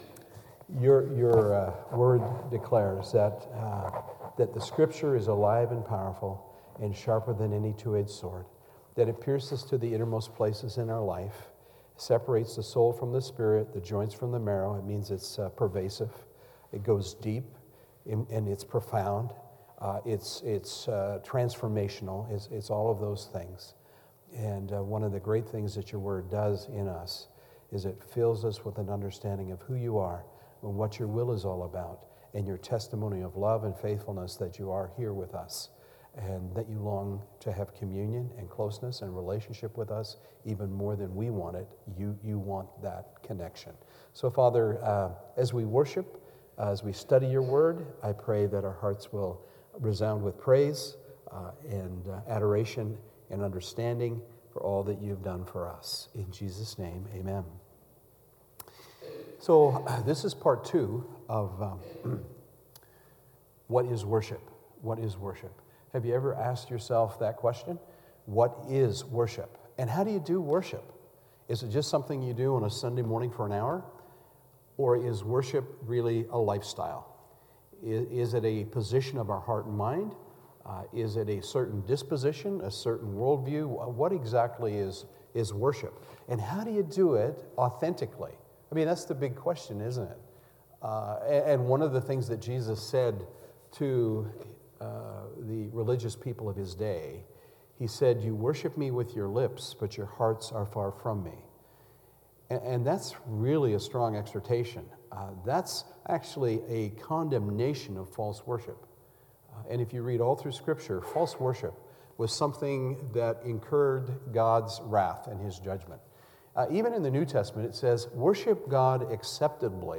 Sermons | Peninsula Mission Community Church